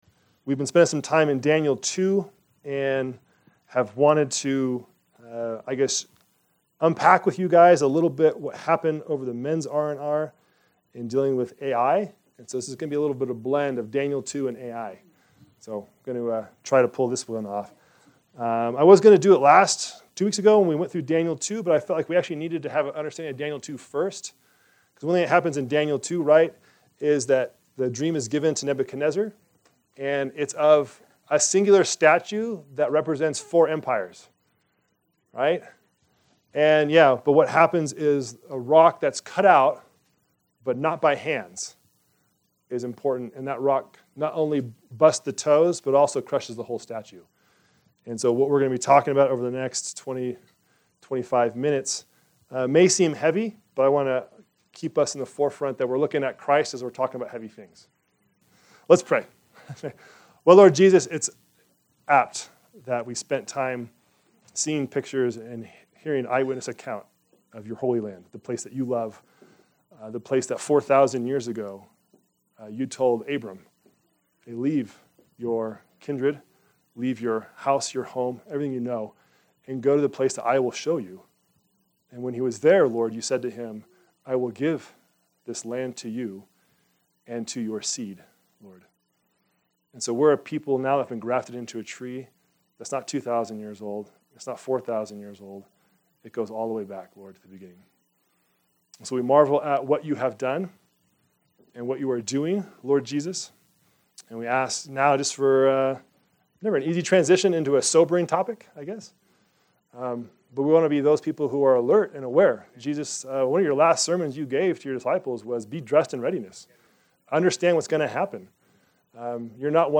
Our Sermons